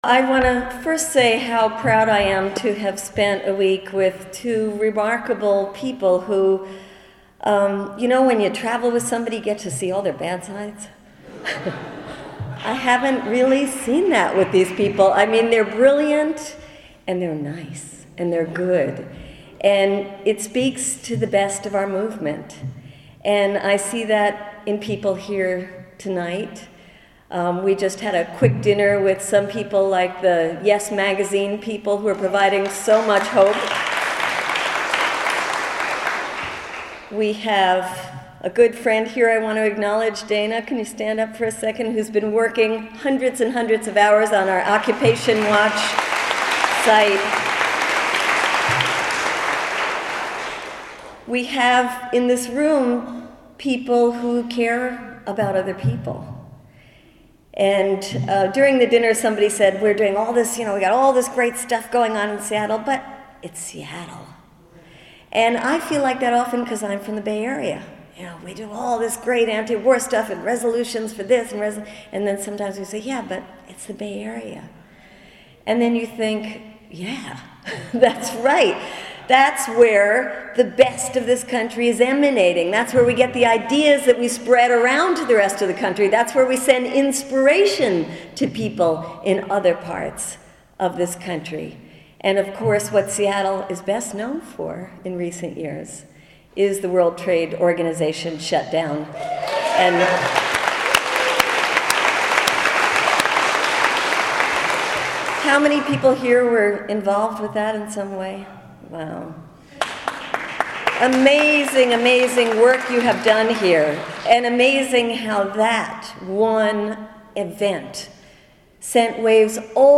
Daniel Ellsberg spoke in Seattle Oct. 1 at the conclusion of an 8-city tour, alongside media critic Norman Solomon and fearless peace activist Medea Benjamin.
Medea Benjamin, Norman Solomon in Seattle